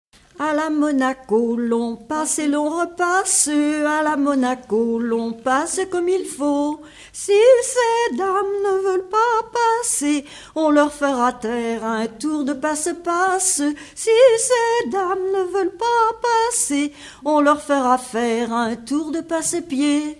Rondes enfantines à baisers ou mariages
Pièce musicale inédite